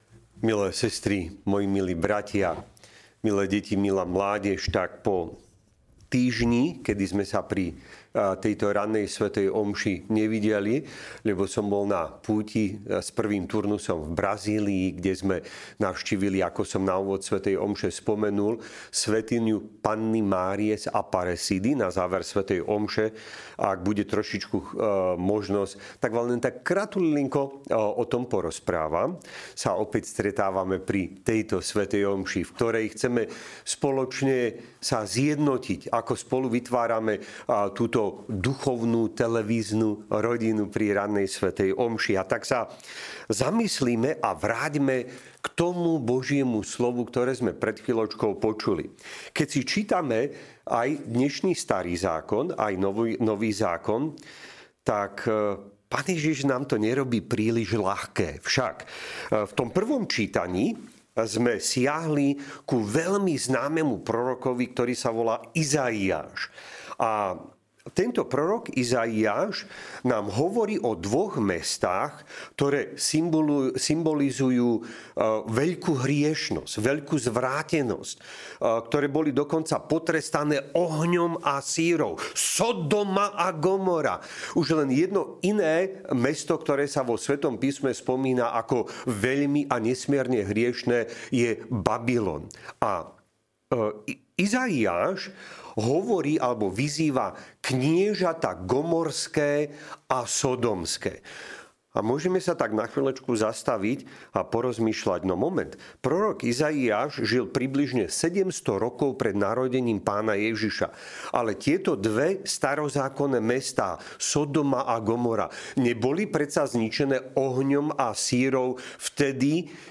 utorok po 2. pôstnej nedeli - káže
Podcasty Kázne POKÁNIE JE BRÁNA BOHA DO NÁŠHO SRDCA